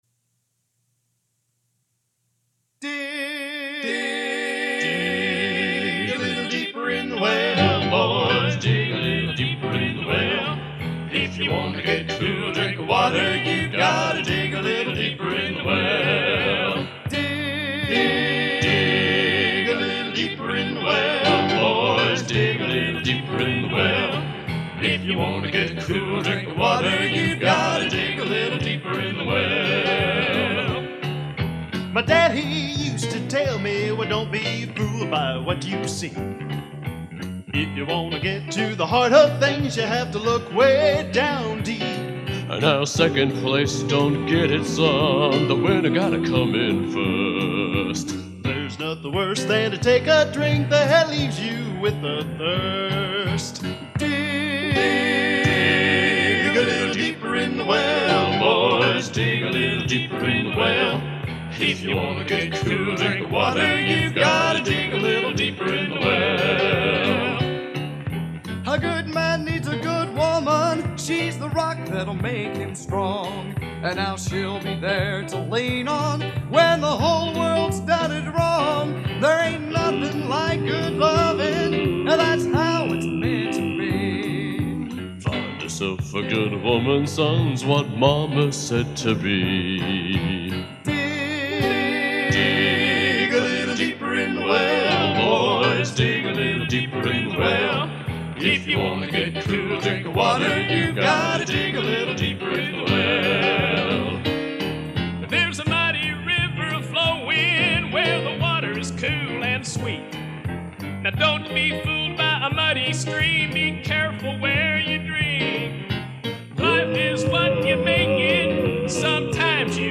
Genre: Gospel | Type: Studio Recording